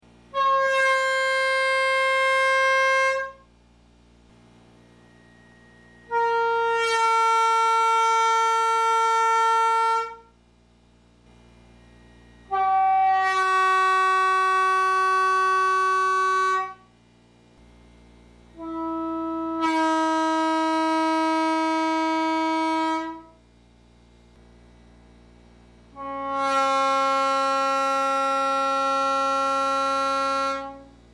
P5A Bells, 1 through 5, individually blown.